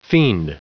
Prononciation du mot fiend en anglais (fichier audio)
Prononciation du mot : fiend